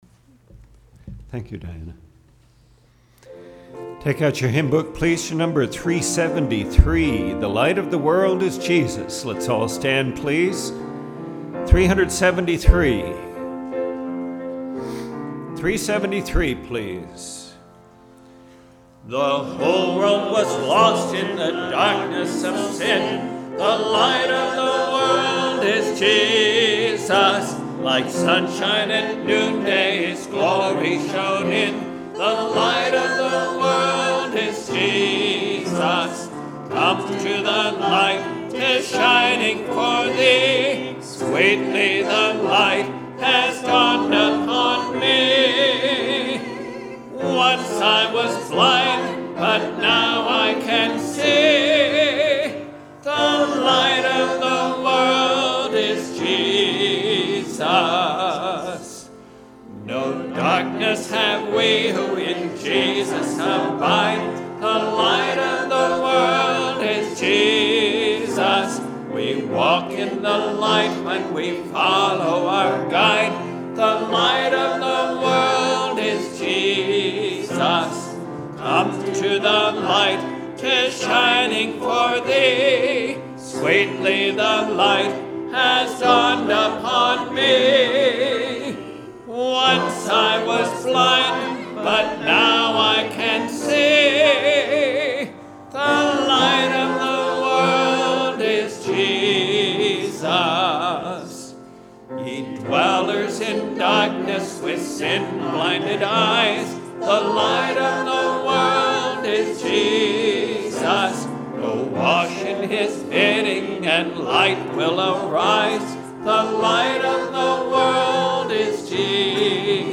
2017 Jehovah Nissi Our Banner Preacher